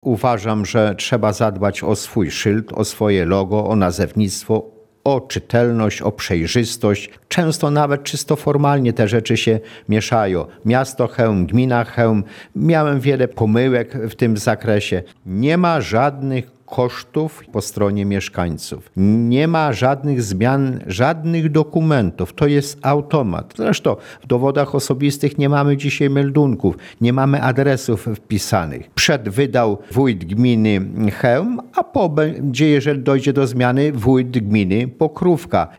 – Nazwa Pokrówka jest częścią naszej historii – mówi wójt Kociuba.